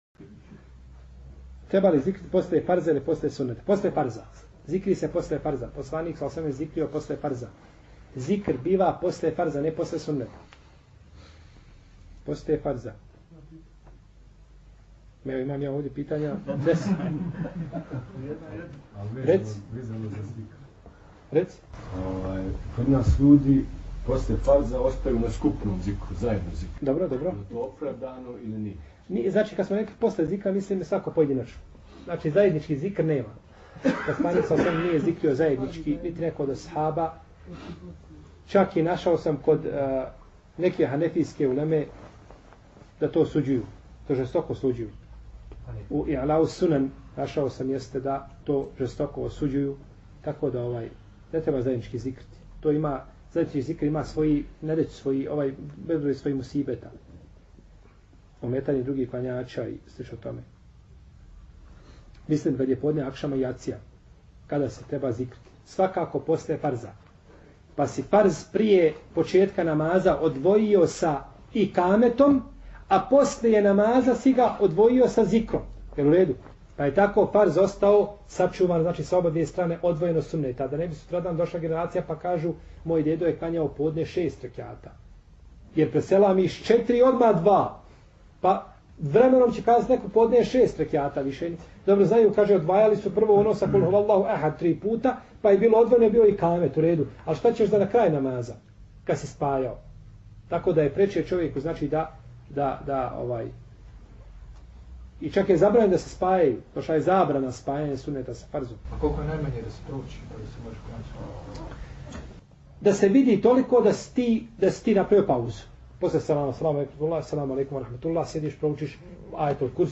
Poslušajte audio mp3 isječak odgovora